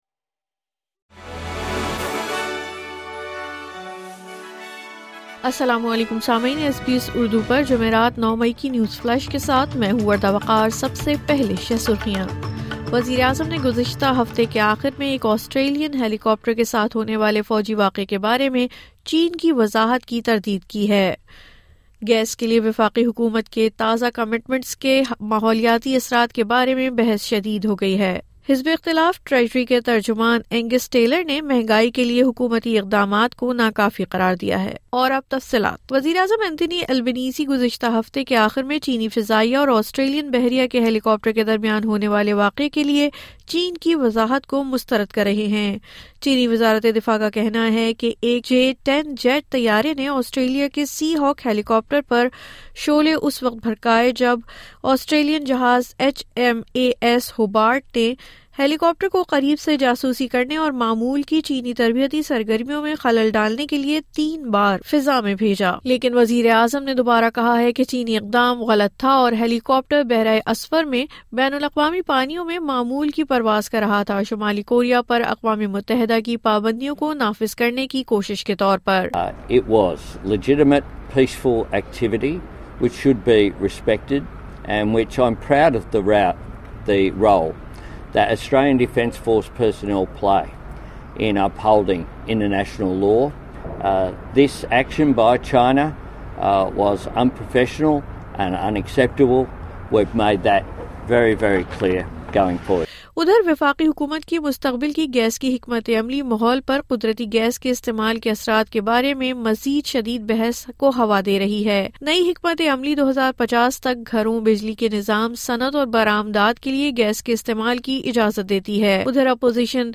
نیوز فلیش:09 مئی 2024: گیس کے لیے وفاقی حکومت کی حالیہ حکمت عملی کے ماحولیاتی اثرات کے بارے میں شدید بحث